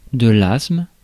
Prononciation
Prononciation France: IPA: /asm/ Le mot recherché trouvé avec ces langues de source: français Traduction 1.